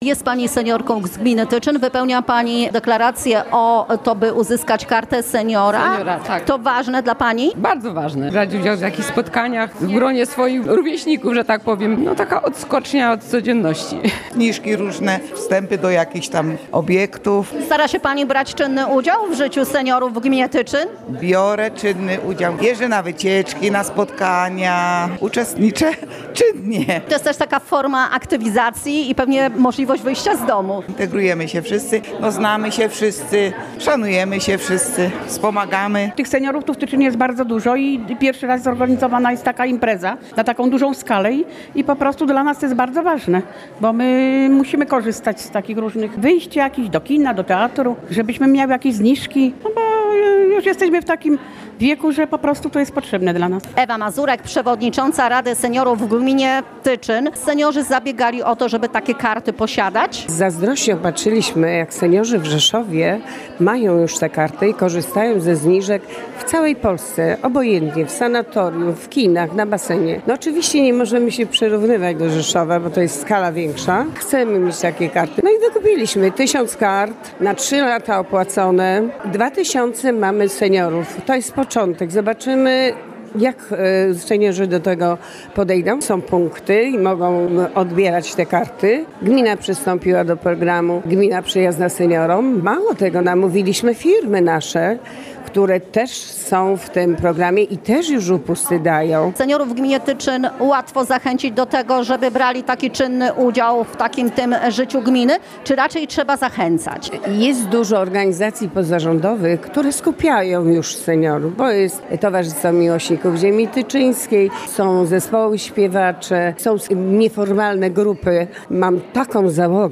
Janusz Błotnicki, burmistrz Tyczyna, informuje również o przystąpieniu tego podrzeszowskiego samorządu do programu „Gmina przyjazna seniorom”.
Relacja